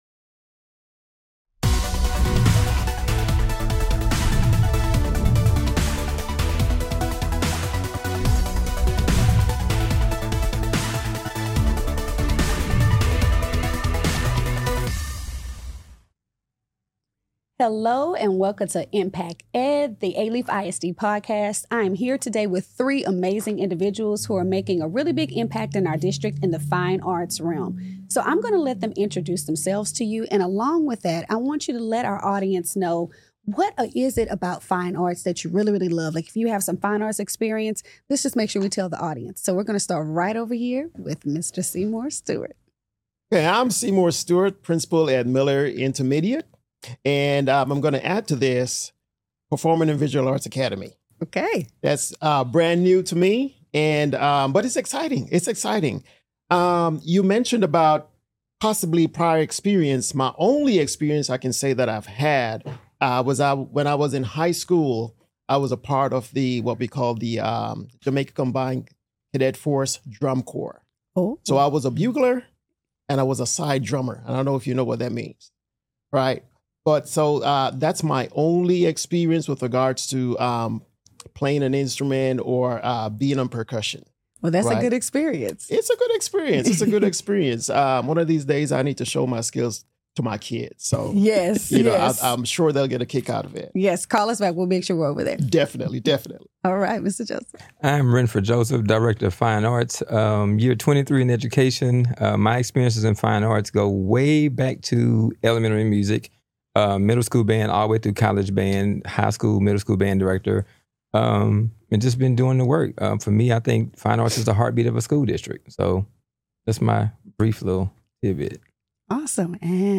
The conversation highlights the role of creativity, self-expression, and the impact of these programs on student engagement and success.